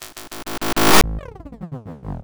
Glitch FX 11.wav